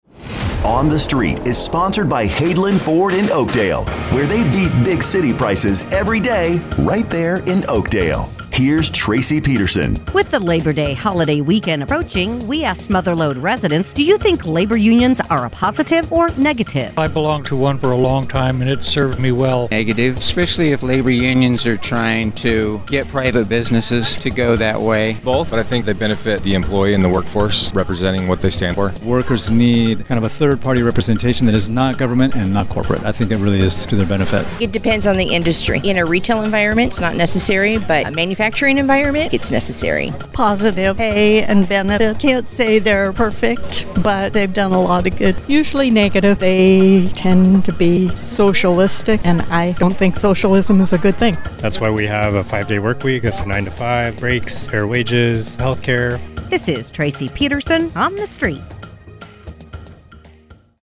asks Mother Lode residents, “Do you think labor unions are a positive or negative?